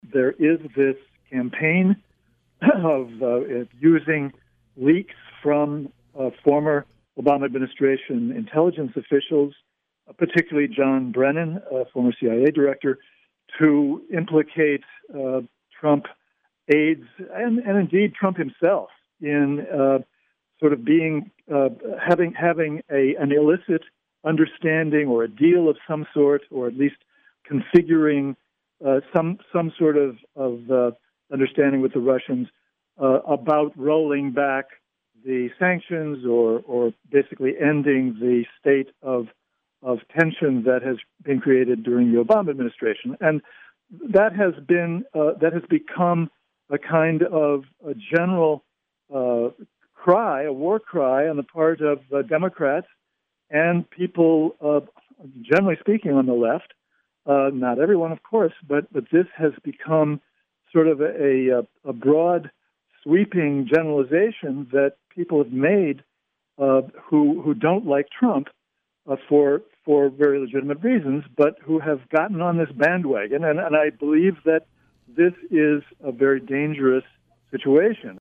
In-Depth Interview: Journalist and Historian Gareth Porter Warns Progressives on Russia Accusations